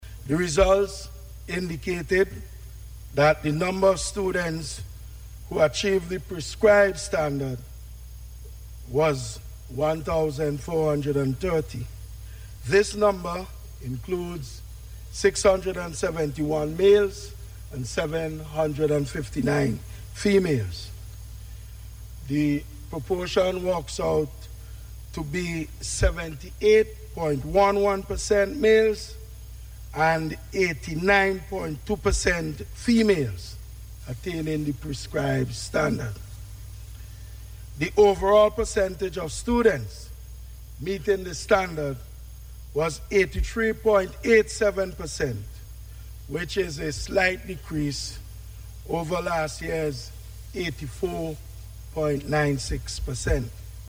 This is according to Minister of Education, Curtis King as he extended congratulations to the students in Parliament this morning.